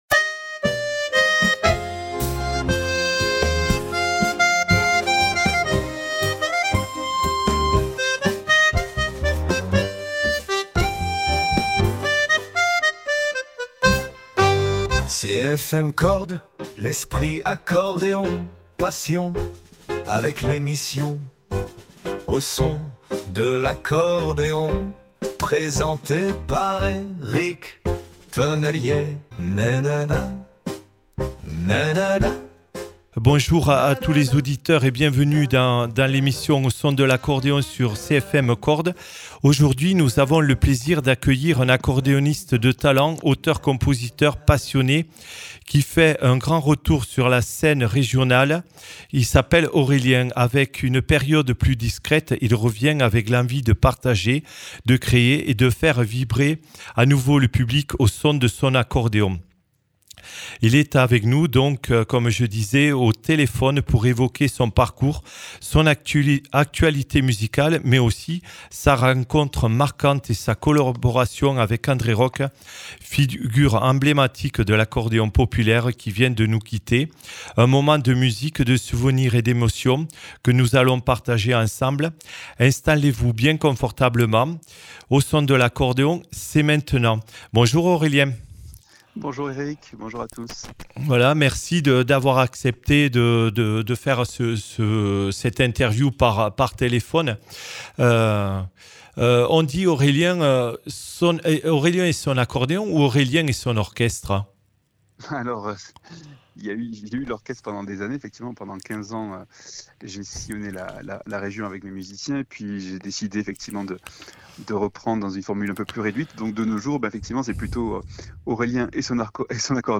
A un grand monsieur de l’accordéon, de la musique, de la joie et la bonne humeur.